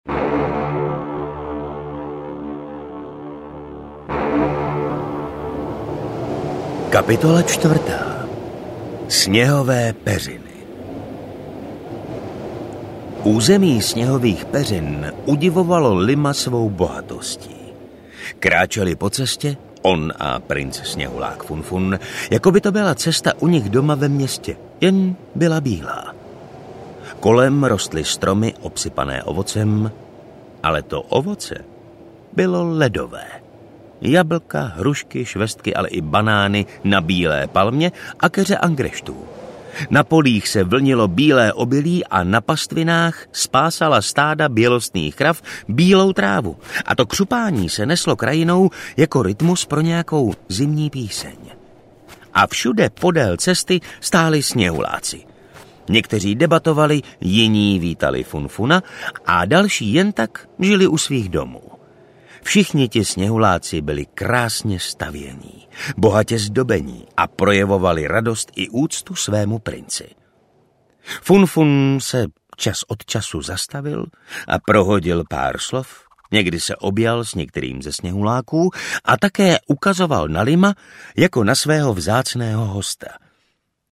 Země sněhuláků audiokniha
Ukázka z knihy